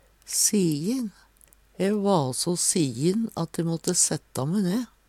DIALEKTORD PÅ NORMERT NORSK sijin trøytt av arbeid Eksempel på bruk E va so sijin at e måtte setta me.